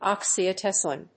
音節ox・y・a・cet・y・lene 発音記号・読み方
/ὰksiəséṭəlɪn(米国英語), `ɔksiəséṭəlɪn(英国英語)/